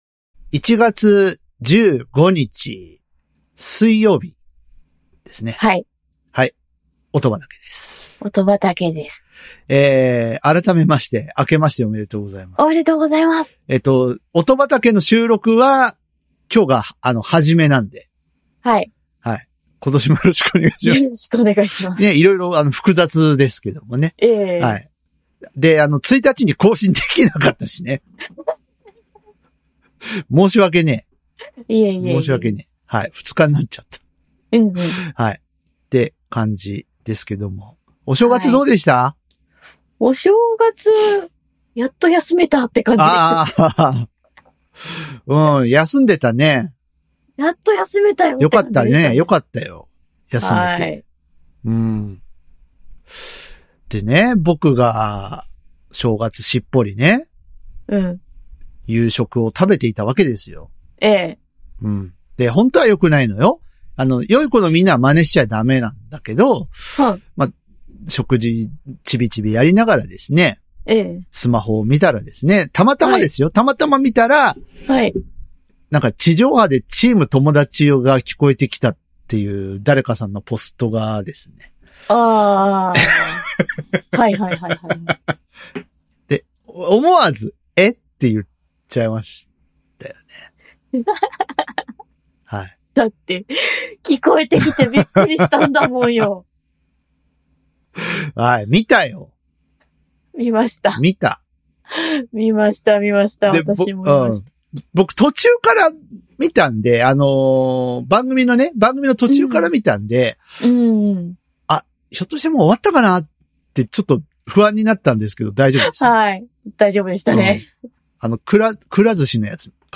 音楽ファンの九州人２人が、毎回一つのテーマに沿って曲を紹介しあうことで良質な音楽を口込んでいく音楽紹介プログラム。